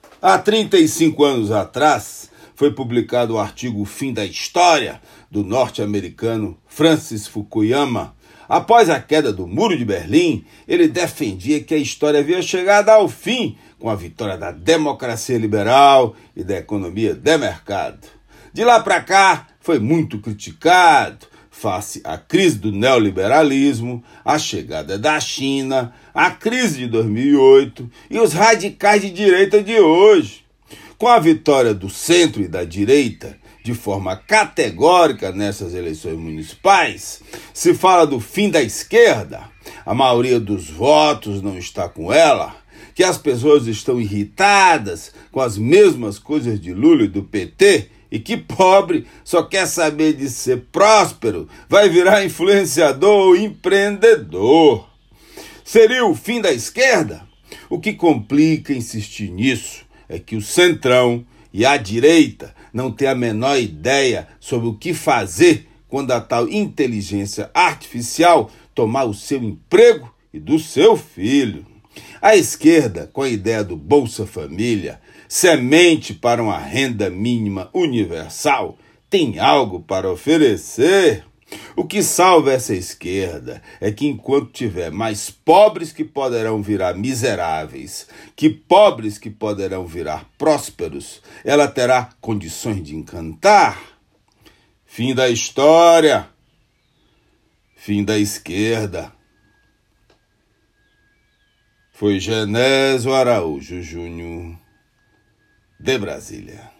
Comentário desta segunda-feira